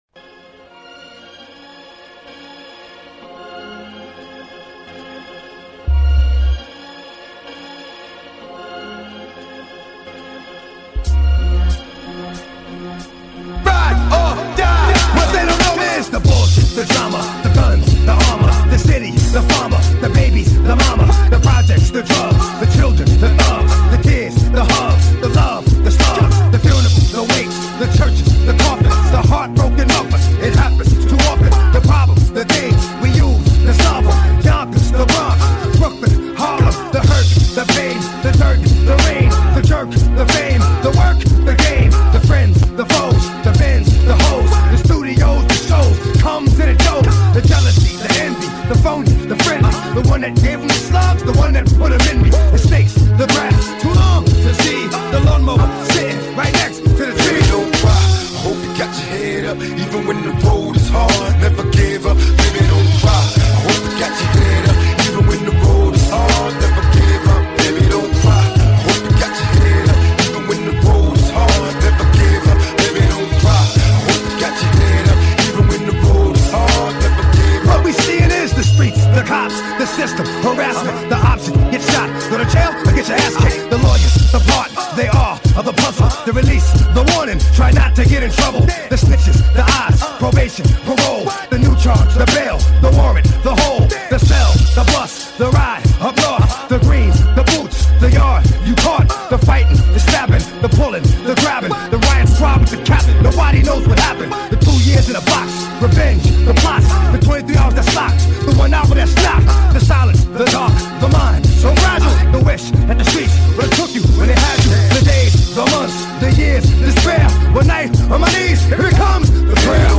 Жанр: Hip-Hop, RAP